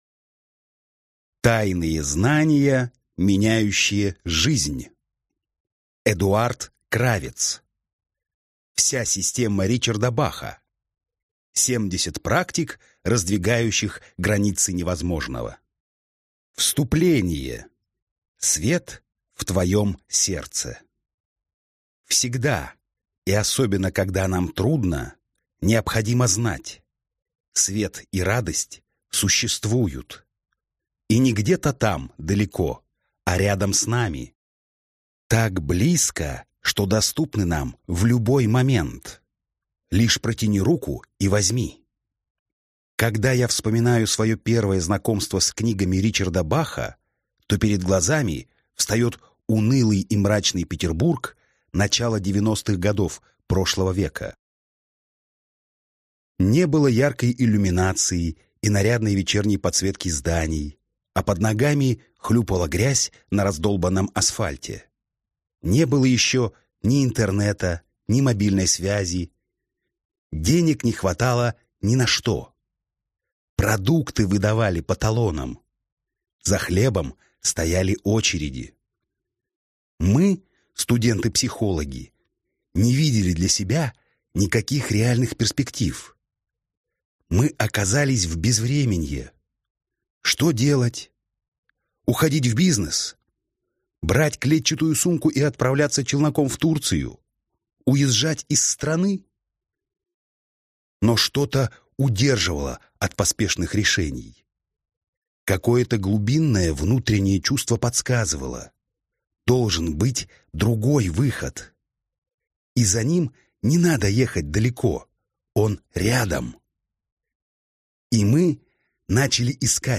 Аудиокнига Вся система Ричарда Баха. 70 практик, раздвигающих границы невозможного!